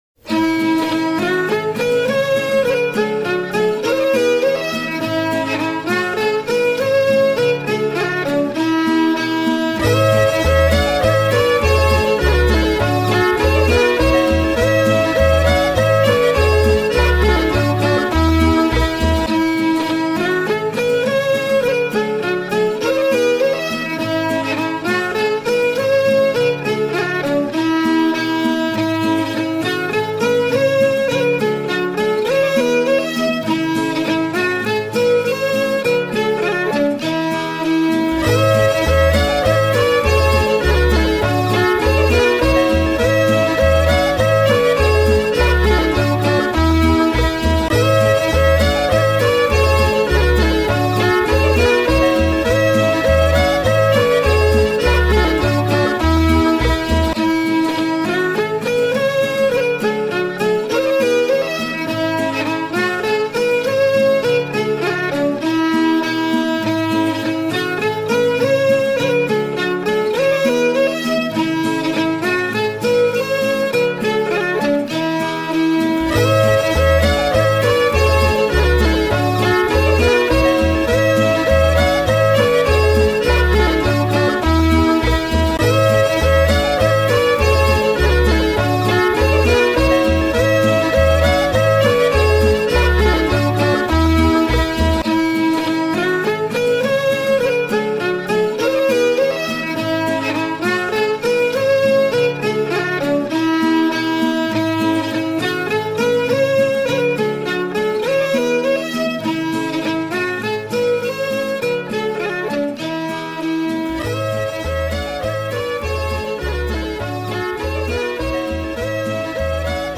la_noce_a_marie_accomp._danse.mp3